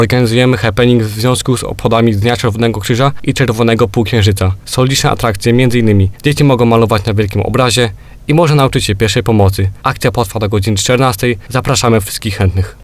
Mówił wolontariusz PCK ze Żnina.